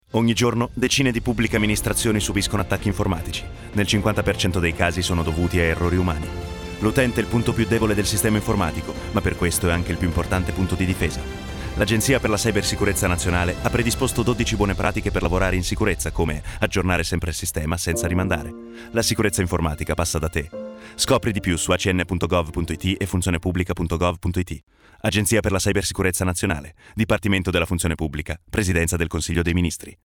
Lo spot radio
spot_-radio-vademecum-cyber-hygiene.mp3